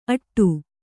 ♪ aṭṭu